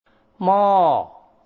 [kadɔɔ] カドー(ｒは発音しません）
[mɔɔ]　お鍋です。（なんとなくわかりそう）